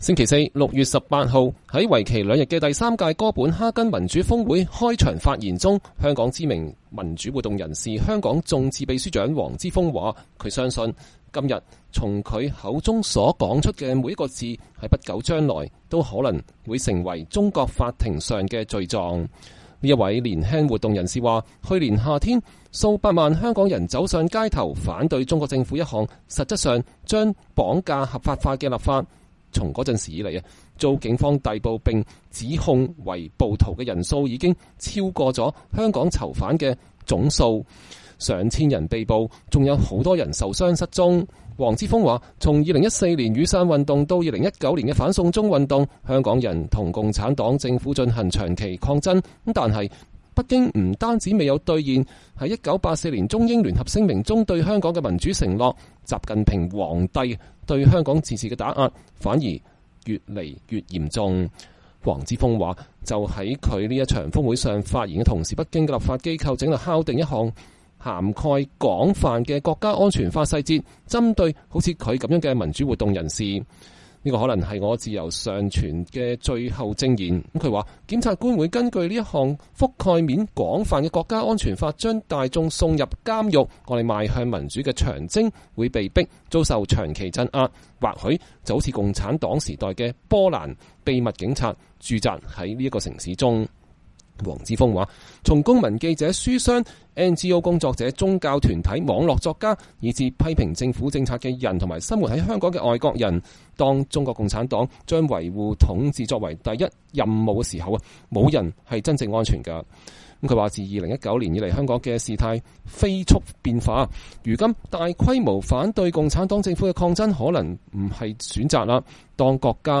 星期四（6月18日），在為期兩天的第三屆哥本哈根民主峰會的開場發言中，香港知名民主活動人士、“香港眾志”秘書長黃之鋒說。